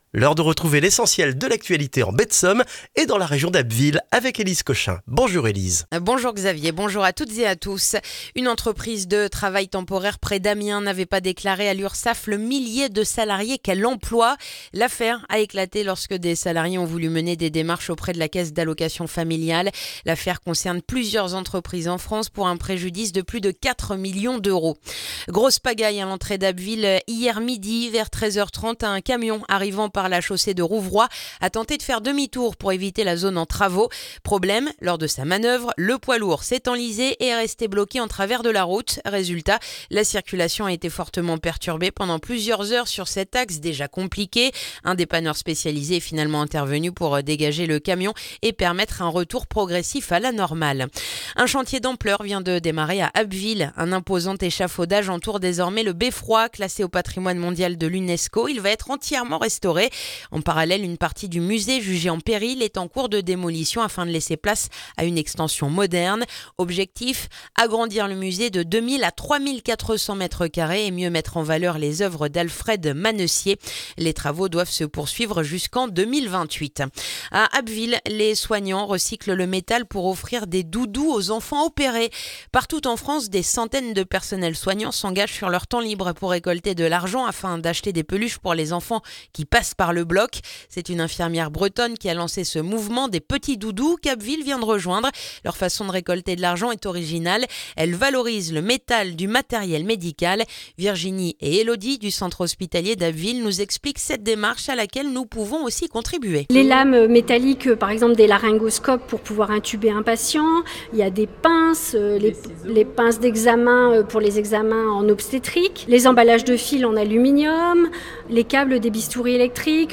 Le journal du jeudi 5 février en Baie de Somme et dans la région d'Abbeville